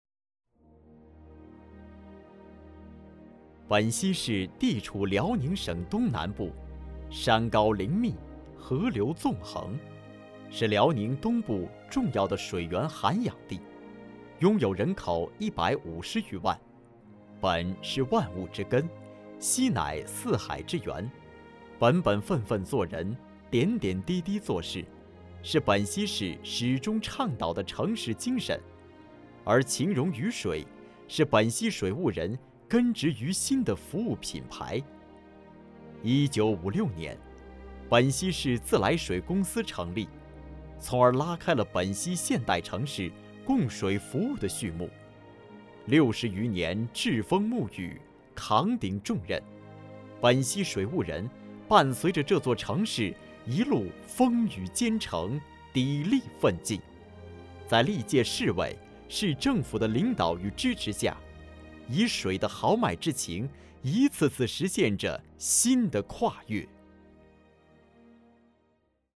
国语青年沉稳 、男广告 、100元/条男3 国语 男声 广告-洋河蓝色经典 稳重 大气 沉稳